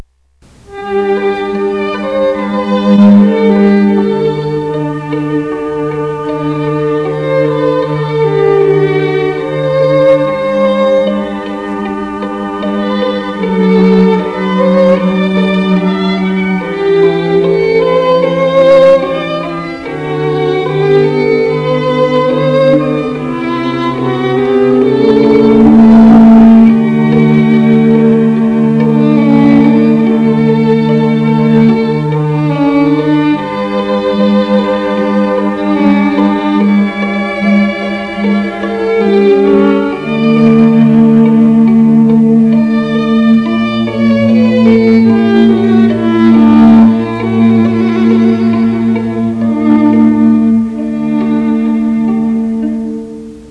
Serenade String Quartet
Baroque Music Samples